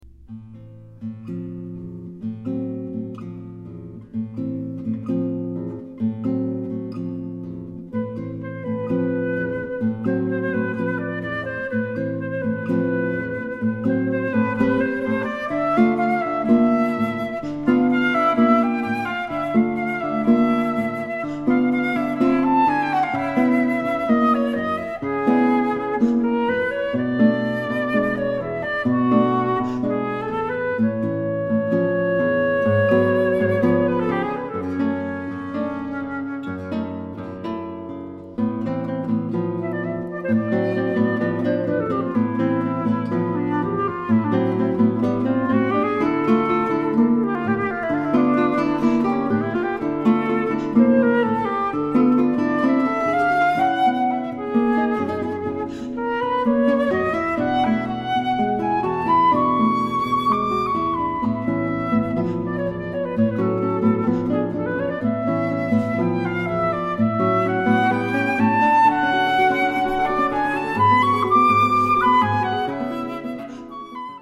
for Flute and Guitar